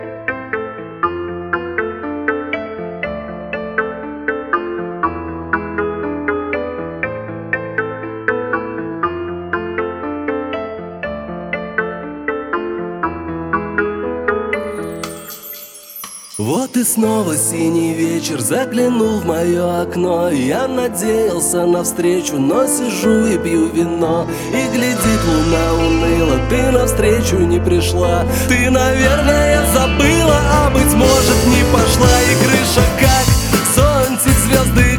Жанр: Музыка мира / Русские